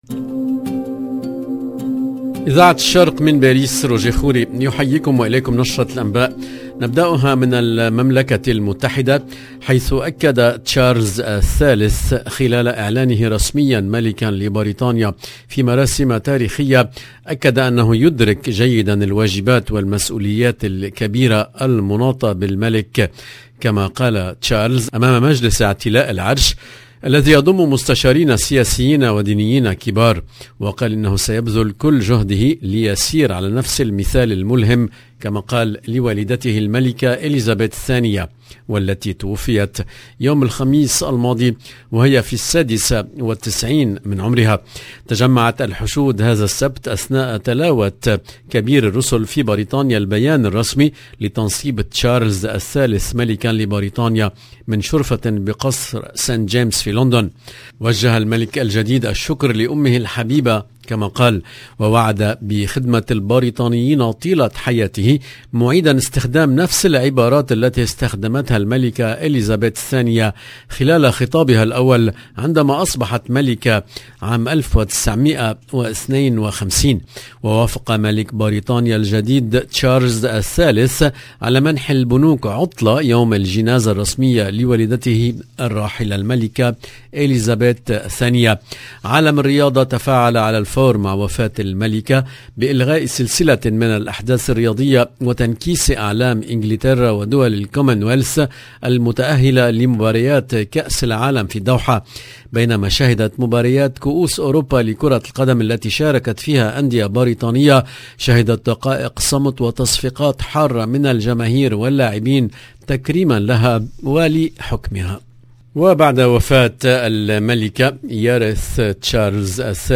LE JOURNAL DU SOIR EN LANGUE ARABE DU 10/09/22